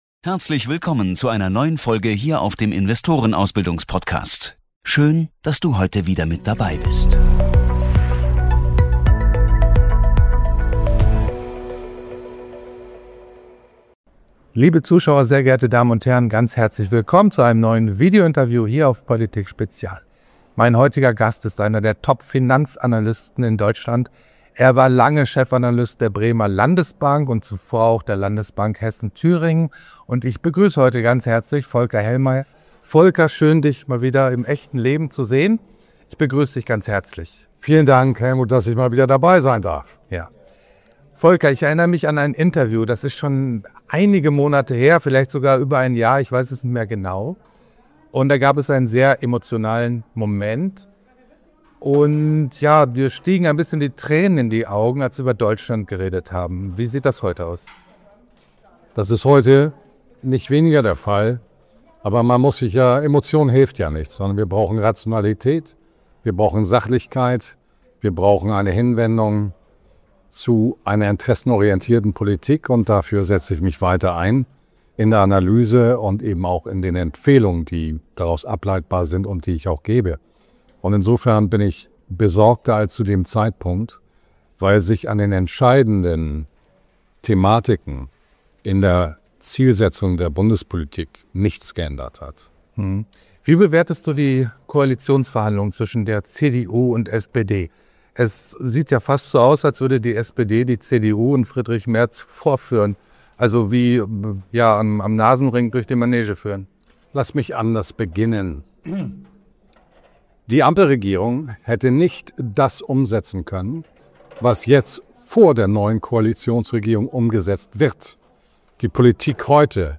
Beschreibung vor 11 Monaten In diesem spannenden Gespräch analysiert der renommierte Finanzanalyst Folker Hellmeyer schonungslos die aktuelle politische und wirtschaftliche Situation Deutschlands. Er kritisiert die Koalitionsverhandlungen, beleuchtet die Demokratiekrise und vergleicht Trumps konsequente Politik mit Europas reaktivem Handeln.